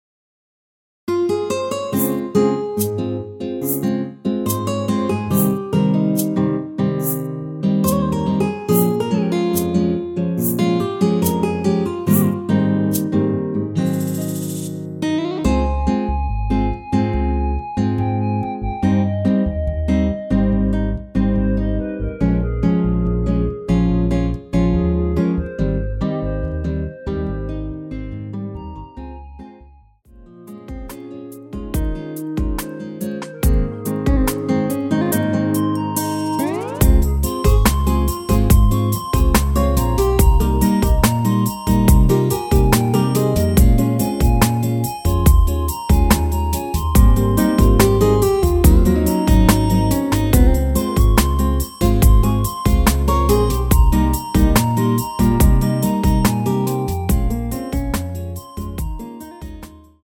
원키에서(-1)내린 멜로디 포함된 MR입니다.(미리듣기 확인)
Ab
노래방에서 노래를 부르실때 노래 부분에 가이드 멜로디가 따라 나와서
앞부분30초, 뒷부분30초씩 편집해서 올려 드리고 있습니다.
중간에 음이 끈어지고 다시 나오는 이유는